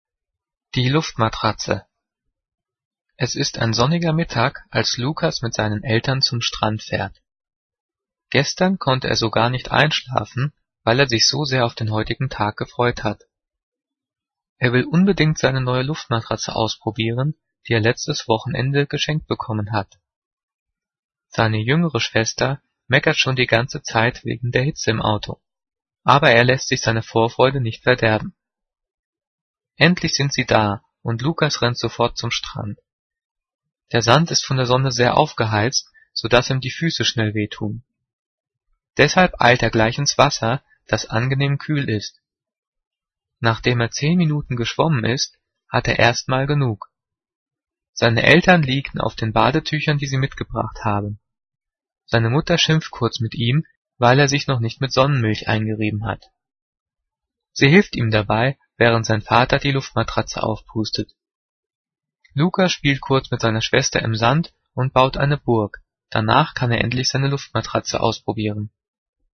Diktat: "Die Luftmatratze" - 5./6. Klasse - Zeichensetzung
Gelesen:
gelesen-die-luftmatratze.mp3